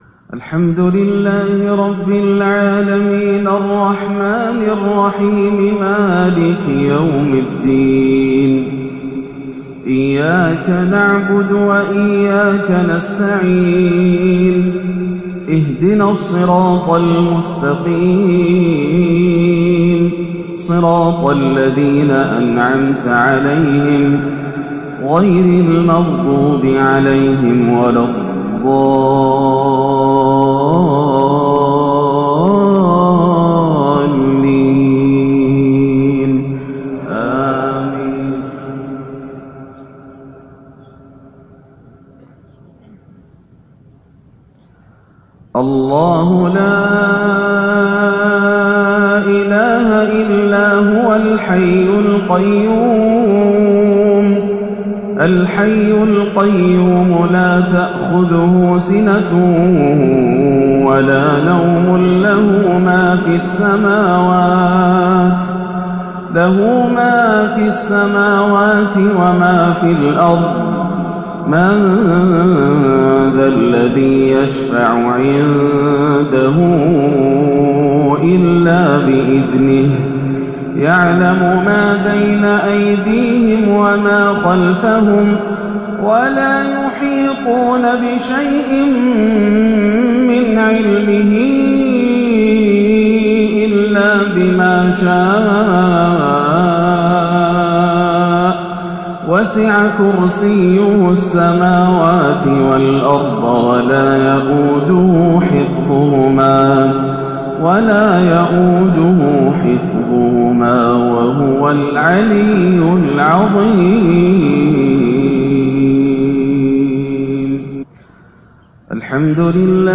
آية الكرسي وما تيسر من سورة "النور" مغربية إبداااعية 9-7-1439هـ > عام 1439 > الفروض - تلاوات ياسر الدوسري